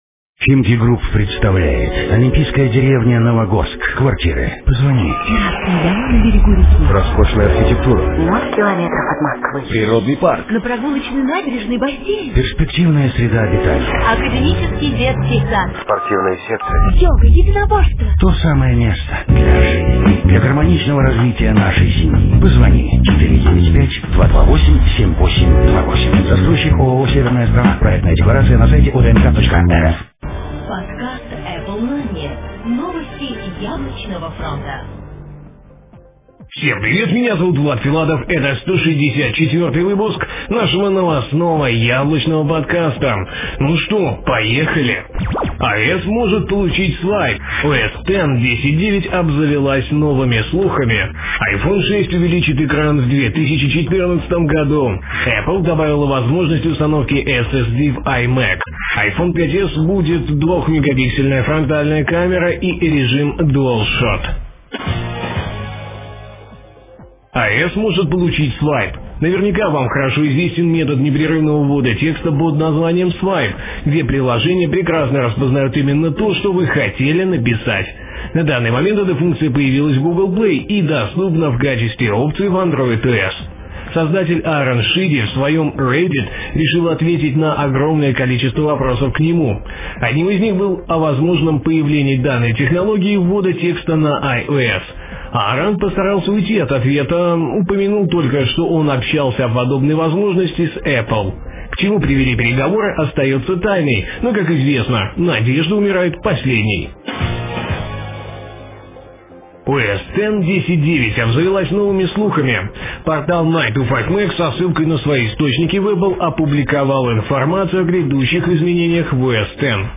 "Apple Mania" - еженедельный новостной Apple подкаст
Жанр: новостной Apple-podcast
Битрейт аудио: 80-96, stereo